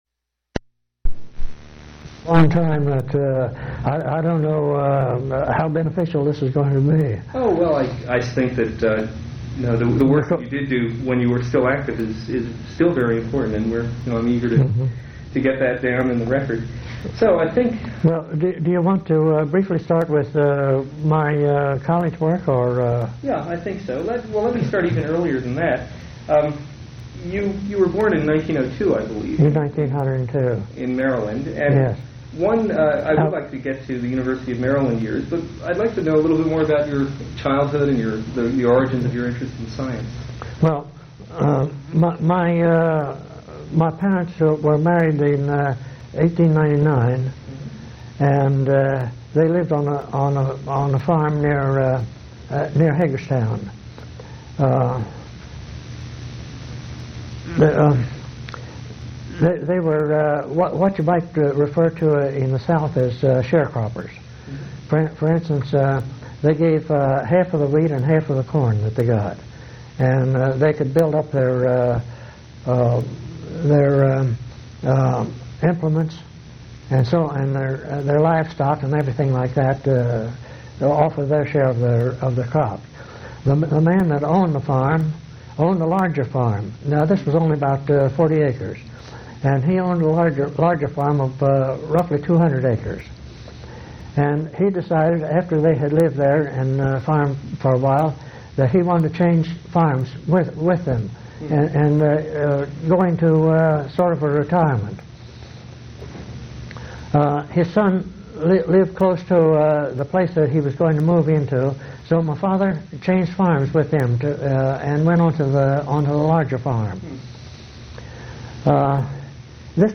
Oral history interview with Russell E. Marker